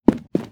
player_sprint.wav